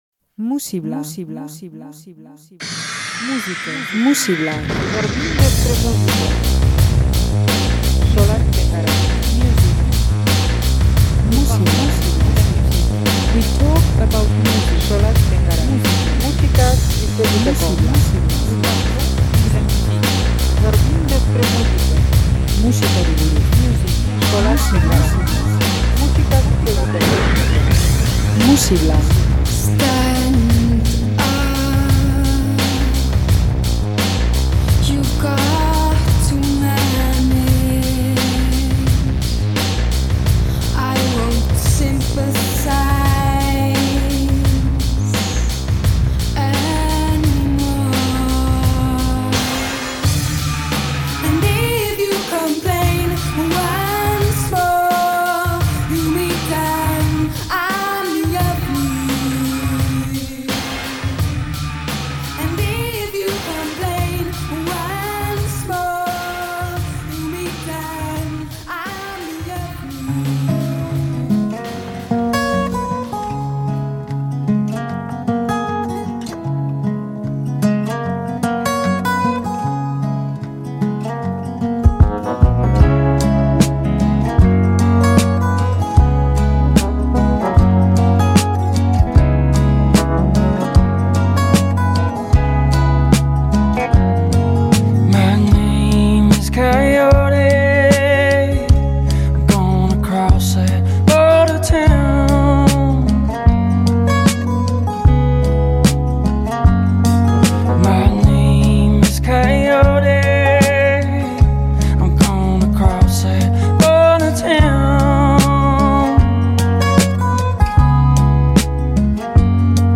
Abesti motzak bezain zuzenak.
minimalismo gozoa